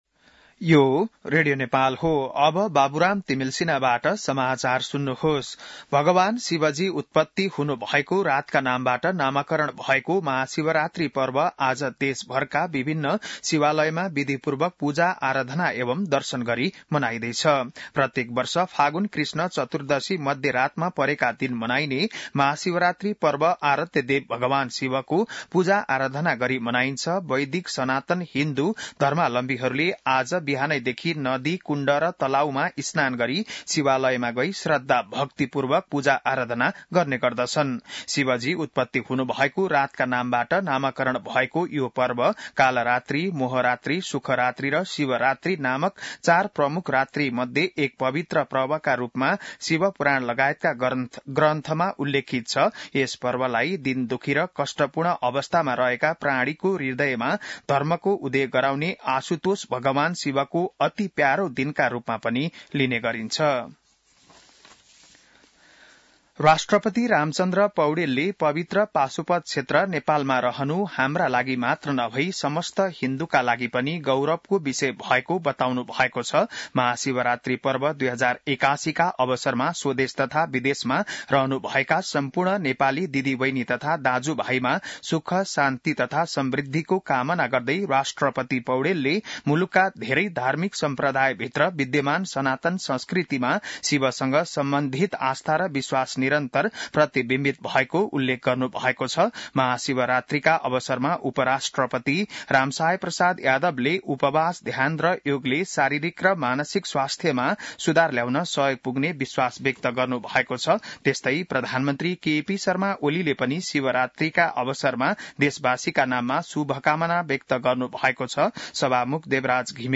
बिहान १० बजेको नेपाली समाचार : १५ फागुन , २०८१